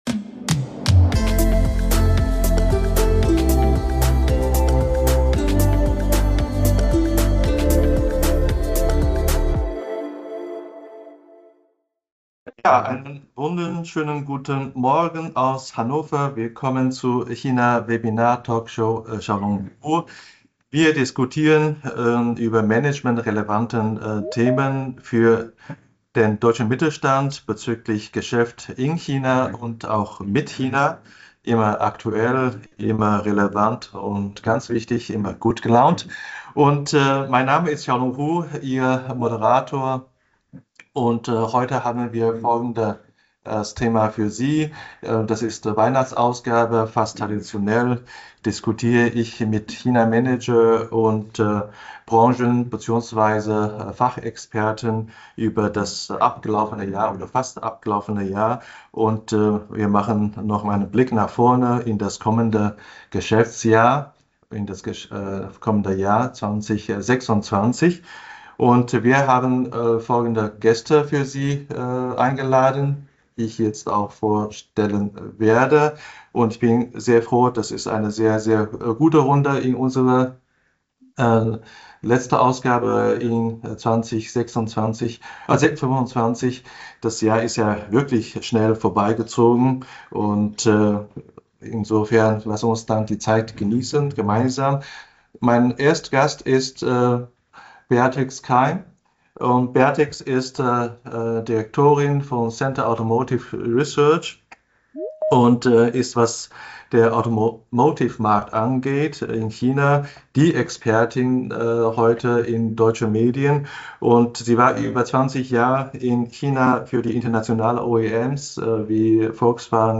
In dieser Episode hören Sie die Live Aufnahme von der Talkshow am 11. Dezember 2025. In dieser Episode diskutieren wir über mögliche Chancen und Herausforderungen im kommenden Jahr.